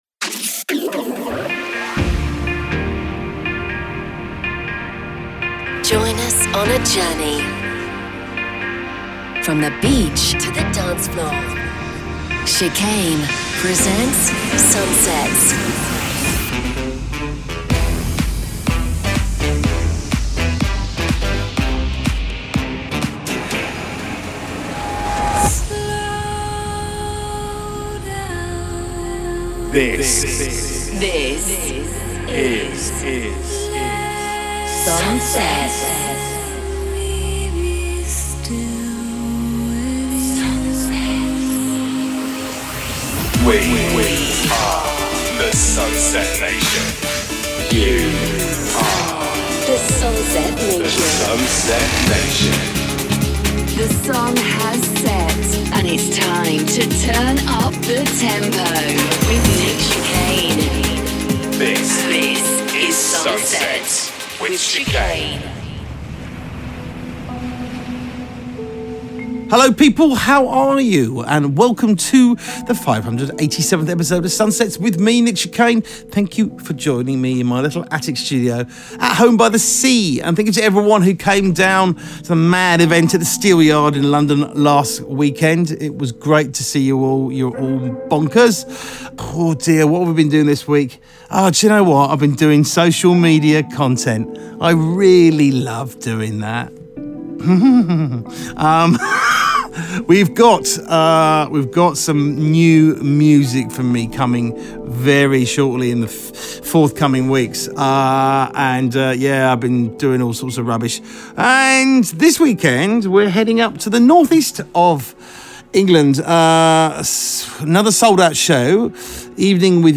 From the beach to the dancefloor…